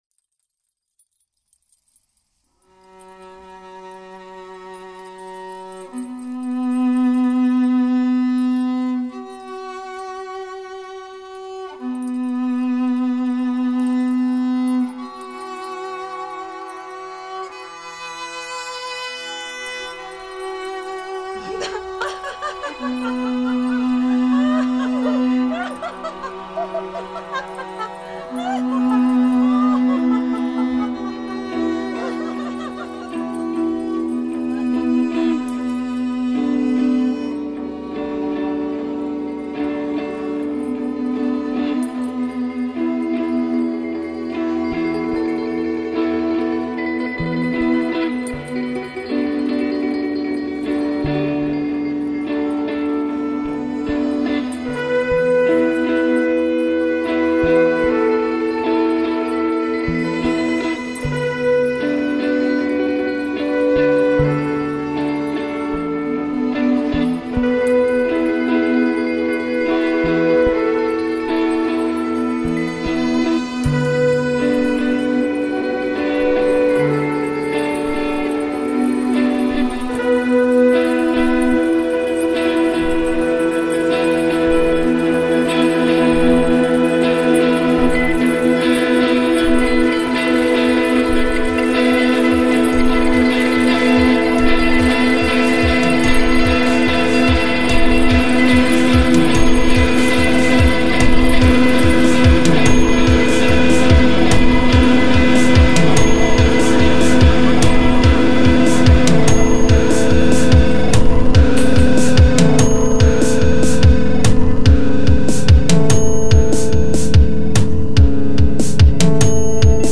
File under: Post-Rock / Electronic / Experimental